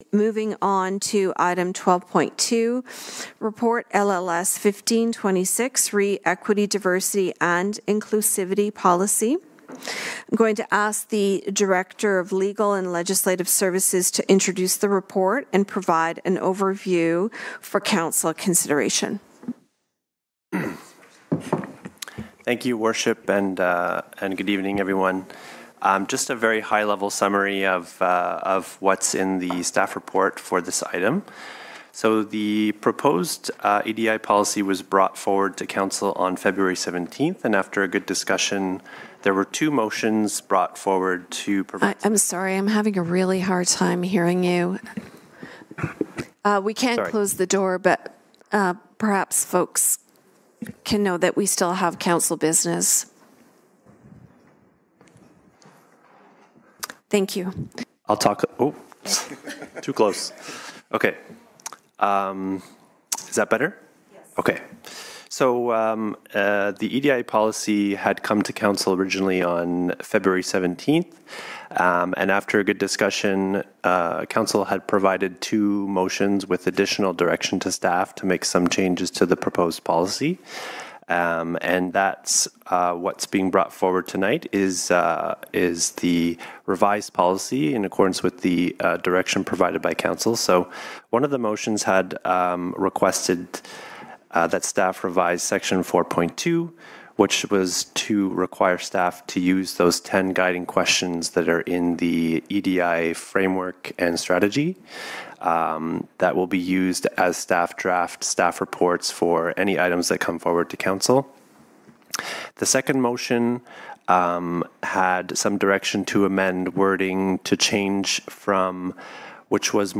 Take a listen to the final presentation of the Port Hope policy and hear the final remarks as it is approved.